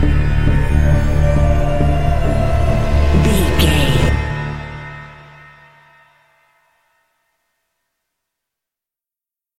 Aeolian/Minor
D
synthesiser
percussion
ominous
dark
suspense
haunting
creepy